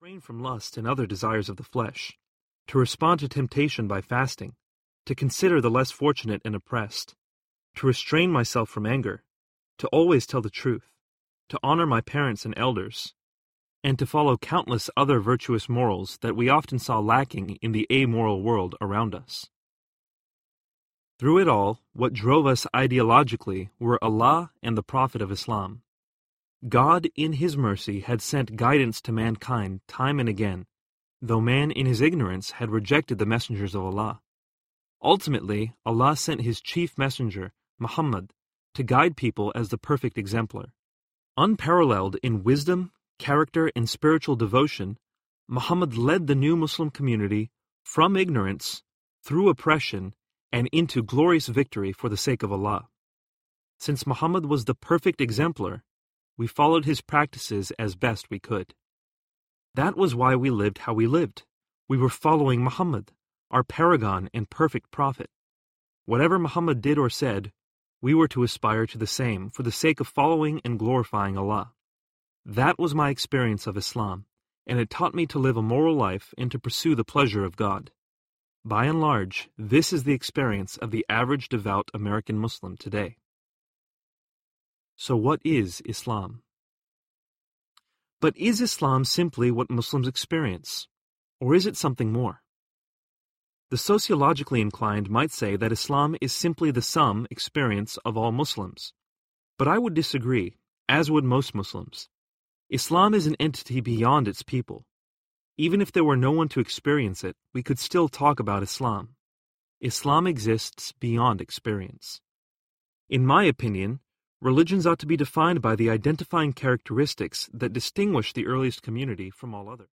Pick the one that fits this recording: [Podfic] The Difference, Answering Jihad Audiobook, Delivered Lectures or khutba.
Answering Jihad Audiobook